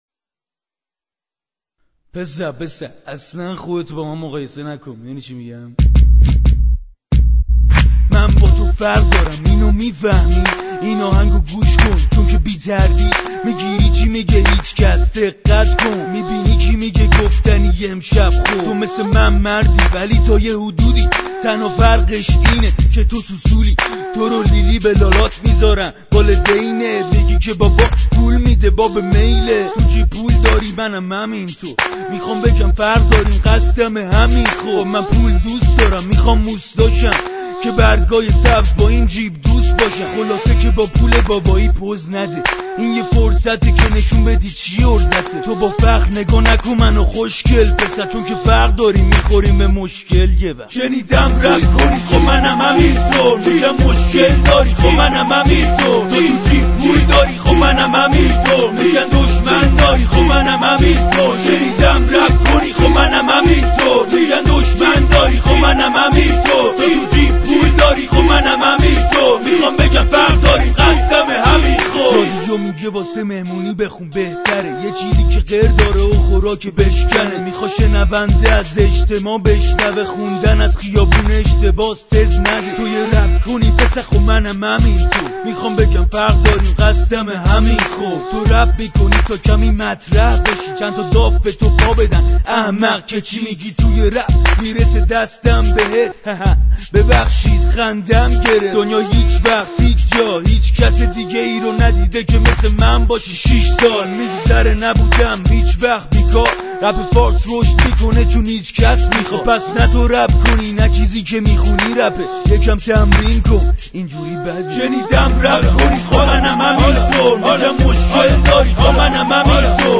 با کیفیت پایین